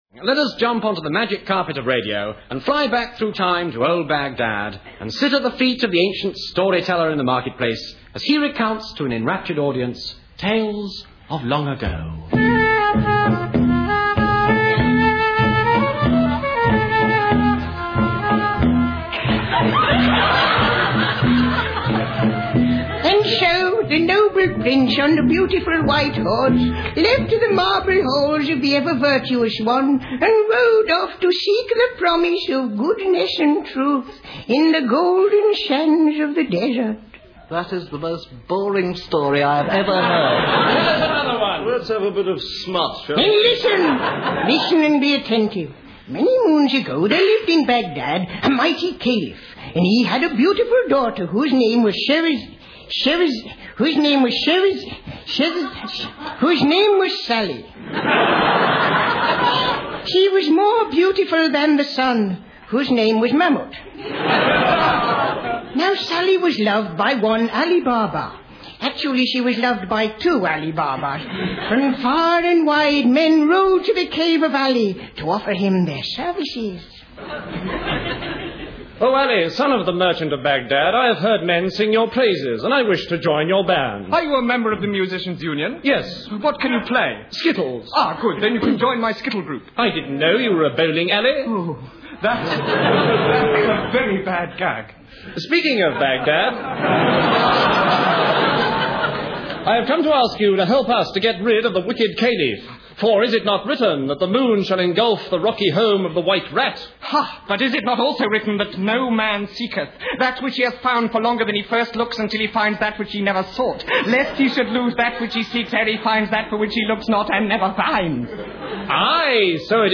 August 18, 2004 Omar Khayyam, Ali Baba and old Baghdad >>> Listen English humor (Monty Python?)...